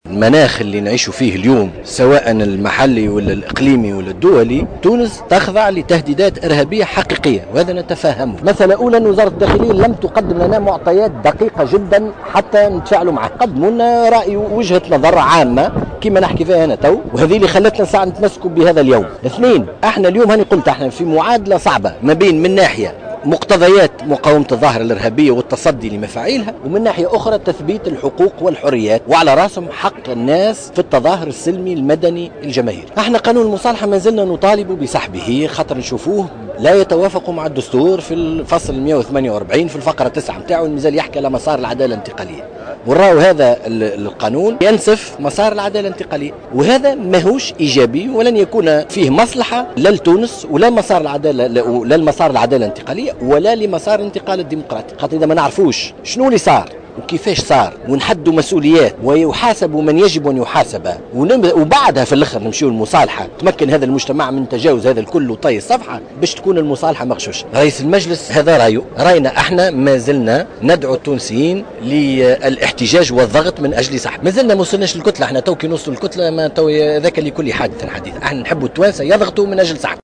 أكد القيادي في الجبهة الشعبية زياد لخضر المشارك في المسيرة المناهضة لقانون المصالحة الاقتصادية اليوم السبت 12 سبتمبر في العاصمة، في تصريح لمراسلة الجوهرة أف أم أن الجبهة مازلت تطالب بسحب قانون المصالحة الاقتصادية لأنه ينسف مسار العدالة الانتقالية وليس فيه مصلحة لتونس ولا لمسار الانتقال الديمقراطي وفق قوله.